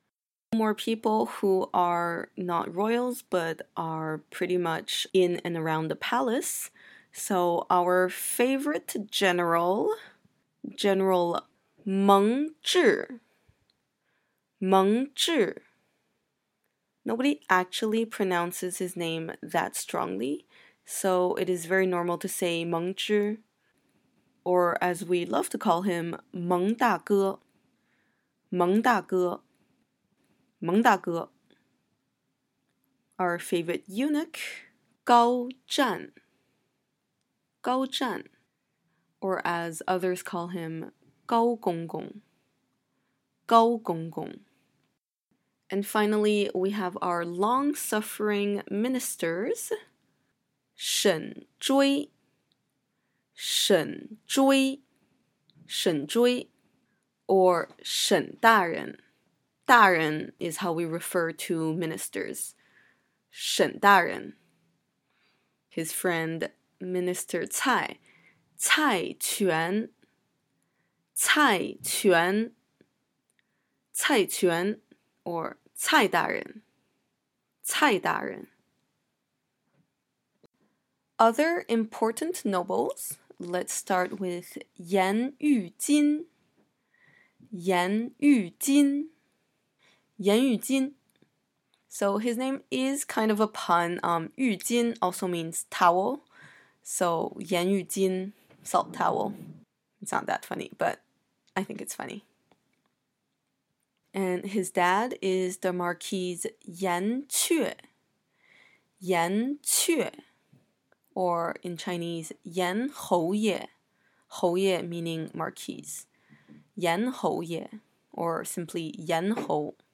Nirvana in Fire pronunciation guide
I believe my accent is fairly Standard but feel free to disagree.